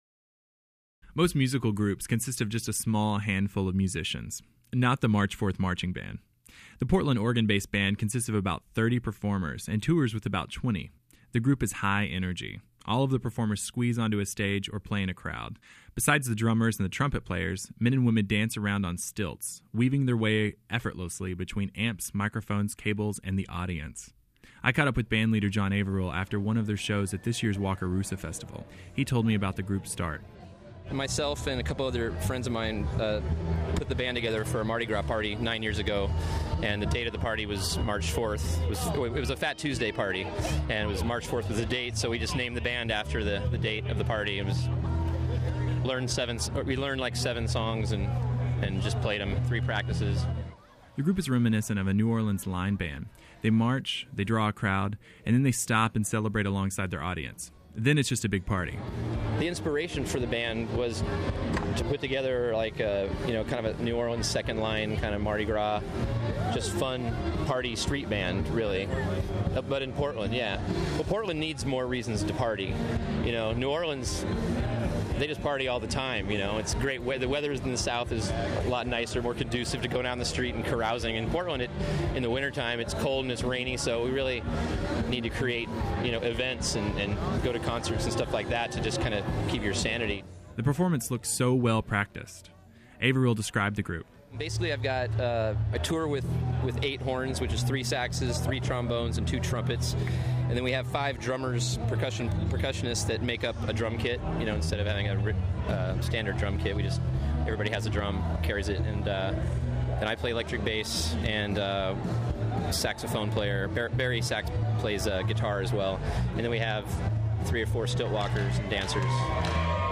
The group, resembling a New Orleans’ line band, is all party.